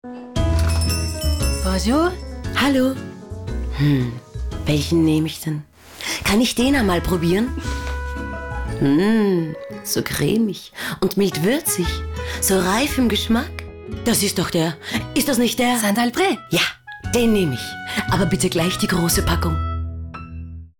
Funk- und TV Werbung Hörspiel Doku Synchro Voice Over
TV Spot